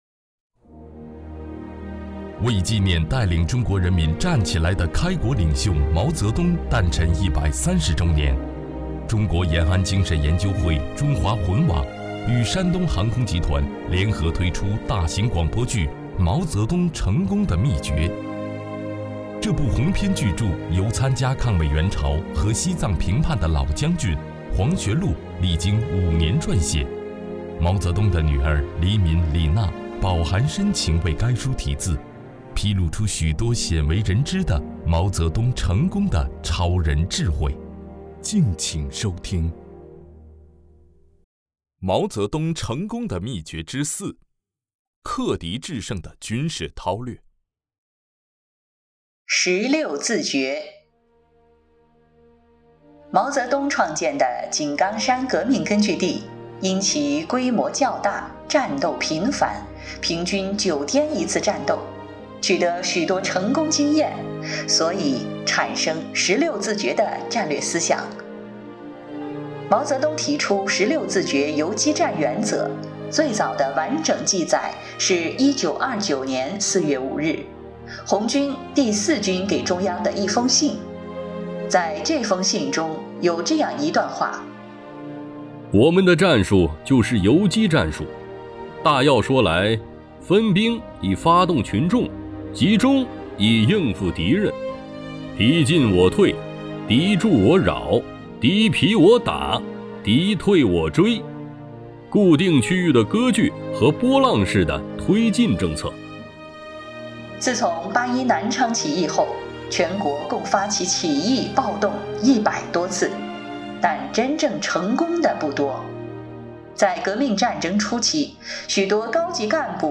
为纪念带领中国人民站起来的开国领袖毛泽东诞辰130周年，中国延安精神研究会《中华魂》网与山东航空集团联合推出大型广播剧《毛泽东成功的秘诀》。